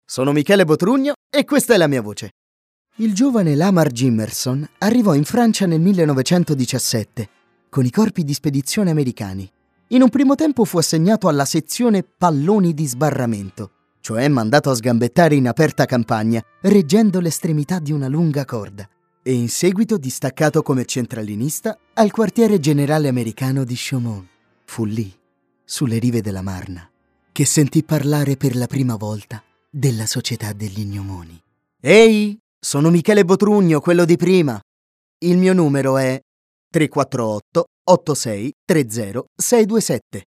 Speaker Italiano- doppiatore italiano- documentario italiano- voice over-radio-film
Kein Dialekt
Sprechprobe: Werbung (Muttersprache):